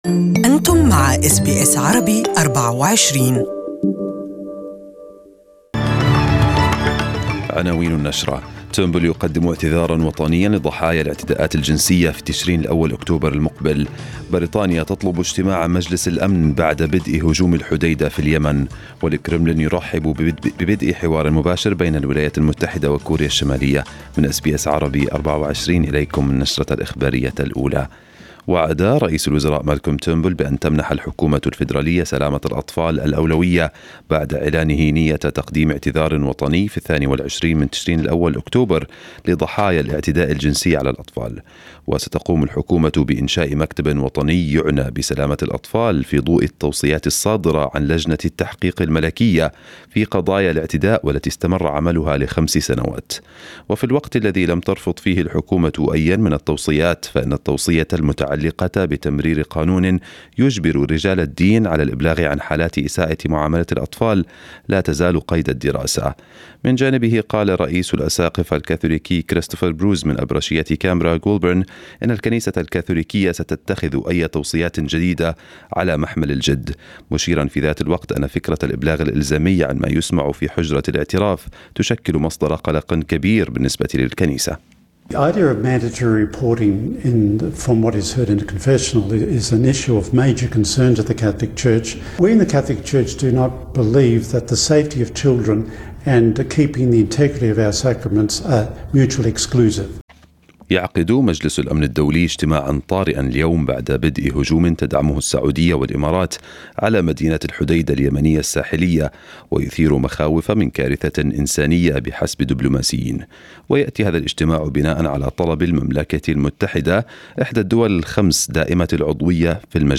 Arabic News Bulletin 14/6/2018